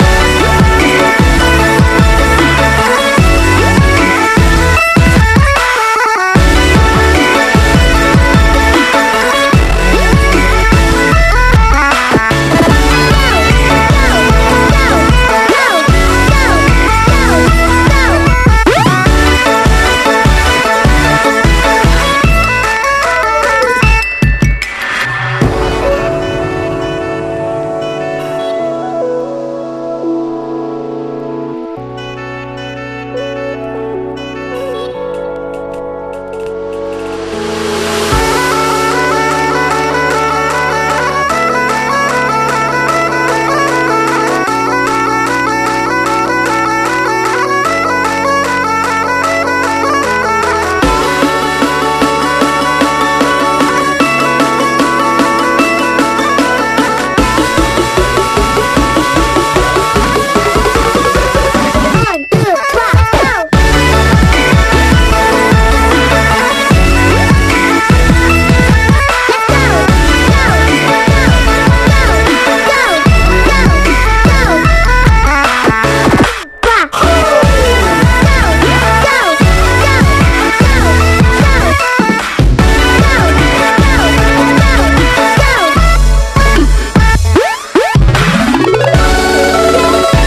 JAPANESE CLUB / JAPANESE HOUSE / BREAKBEATS